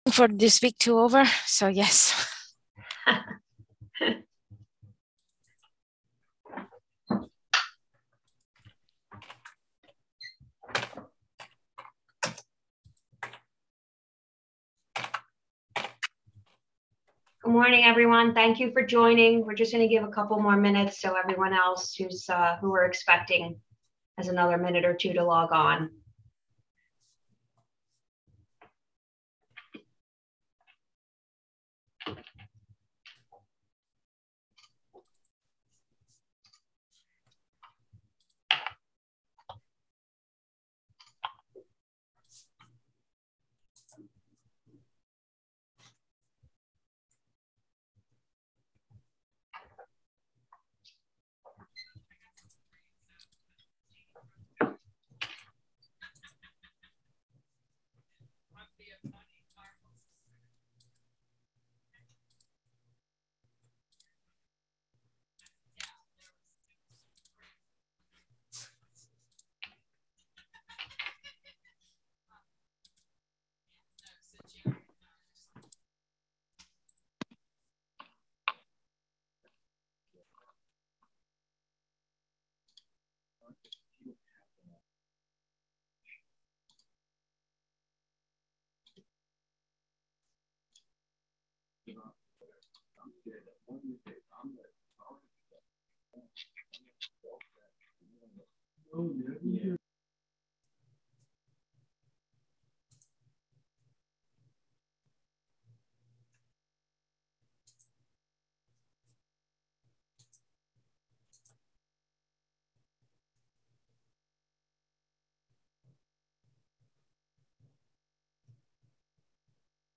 DAFB Compatible Use Study-Technical and Policy Joint Committee Meeting